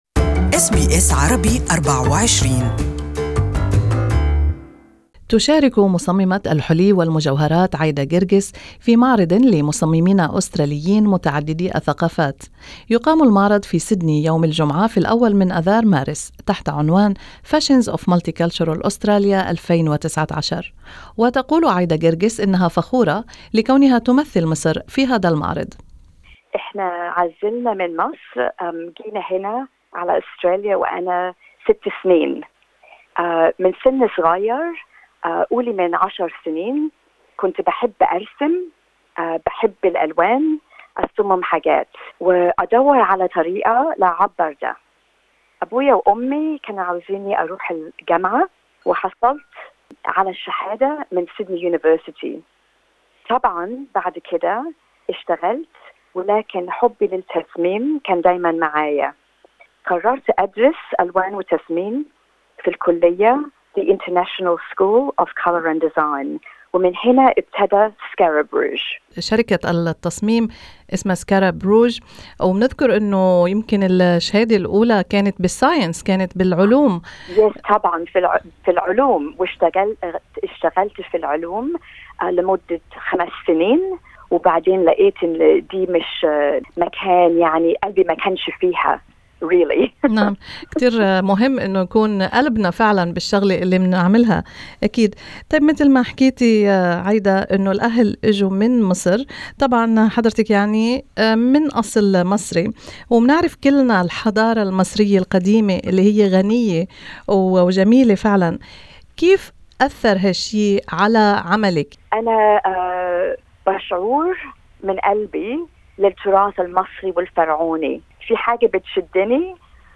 Listen to both interviews in Arabic.